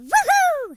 share/hedgewars/Data/Sounds/voices/HillBilly/Ow2.ogg
Ow2.ogg